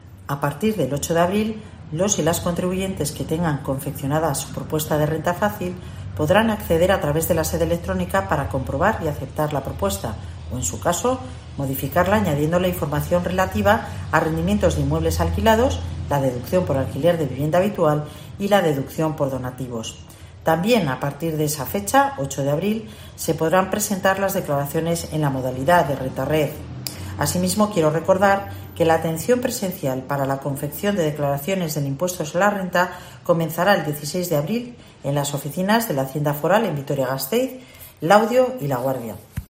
Itziar Gonzalo, diputada de Hacienda de Álava, da detalles de la campaa de la Renta